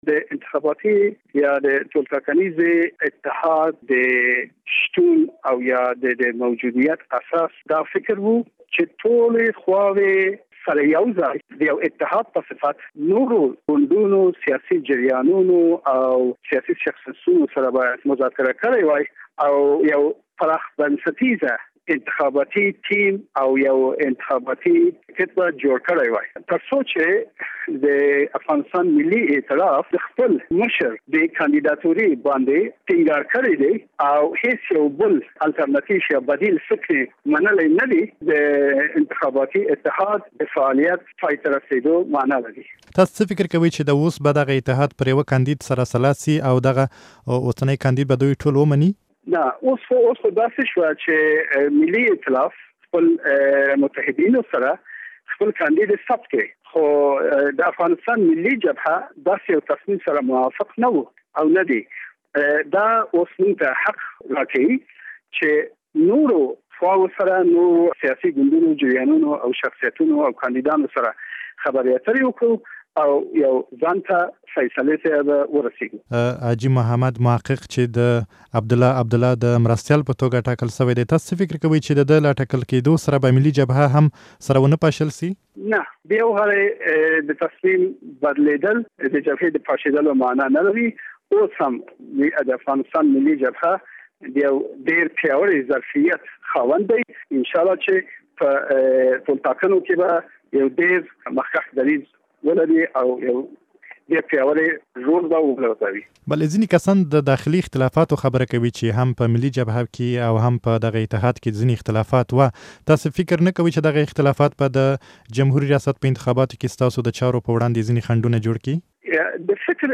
له فيض الله ذکي سره مرکه